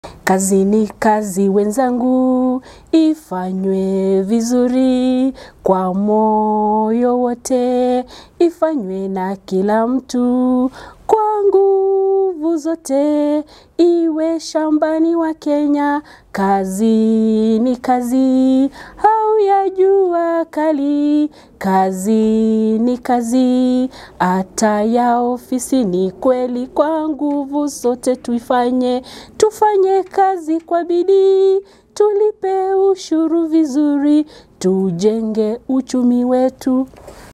NYIMBO_ZA_KAZI.mp3